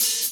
Dry_Ohh.wav